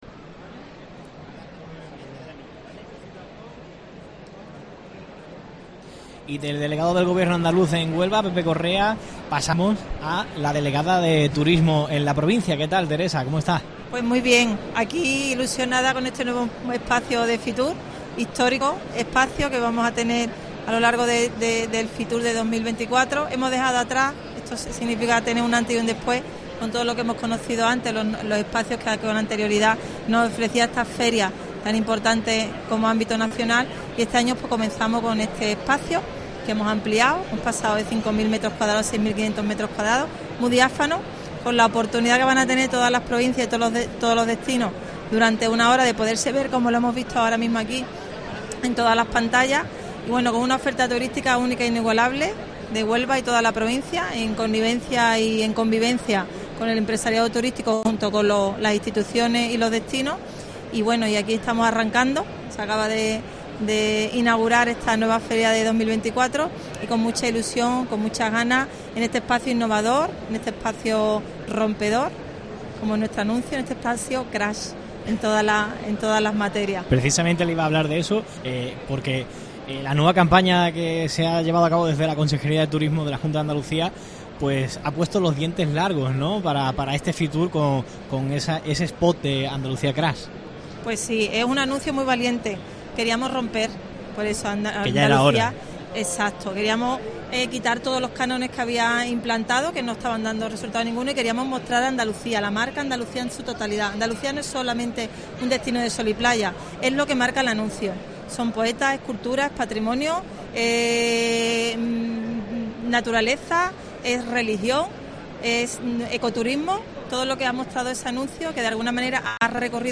La delgada de Turismo, Cultura y Deporte en Huelva ensalza los valores de Andalucía en los micrófonos de COPE Huelva
Entrevista completa a Teresa Herrera, delegada de Turismo de la Junta en Huelva